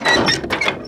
GearDown.wav